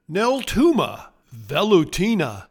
Pronounciation:
Nel-TOO-ma vel-u-TEE-na